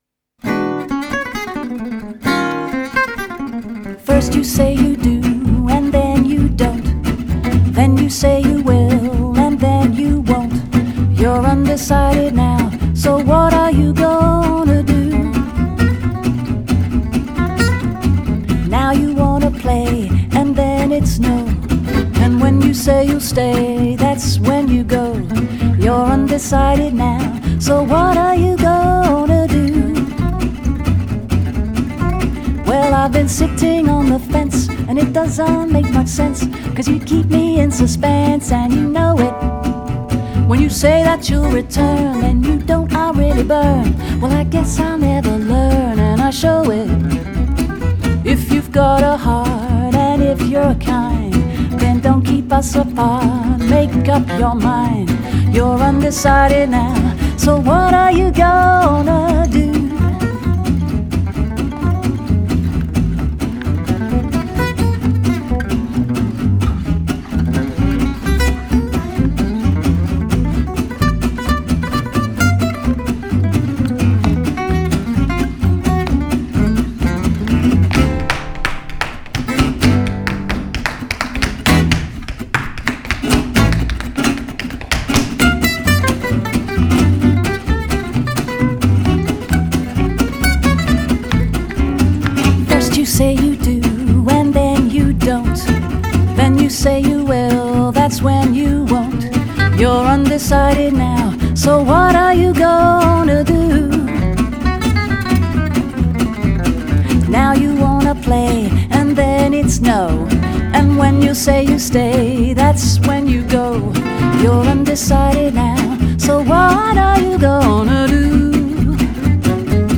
Accompagnée d’un solide trio issu du jazz manouche
chant, claquettes, percussions
guitare solo
guitare rythmique
contrebasse